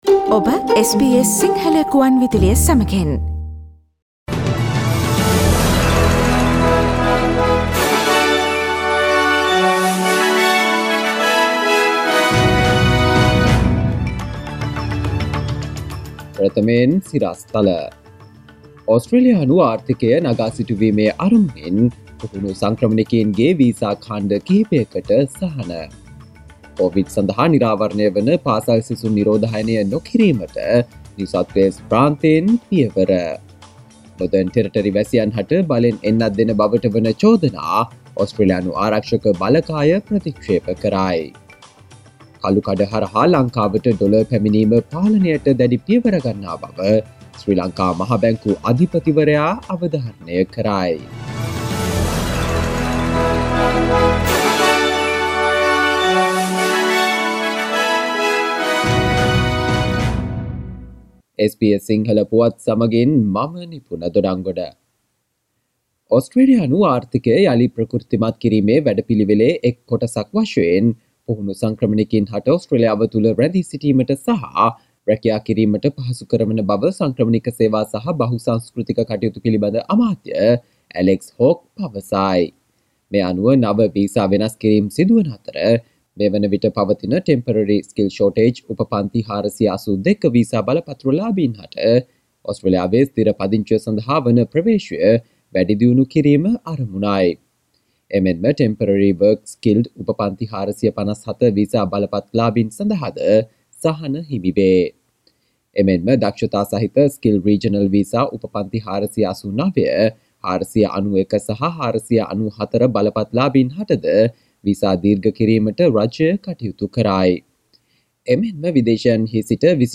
සවන්දෙන්න 2021 නොවැම්බර් 26 වන සිකුරාදා SBS සිංහල ගුවන්විදුලියේ ප්‍රවෘත්ති ප්‍රකාශයට...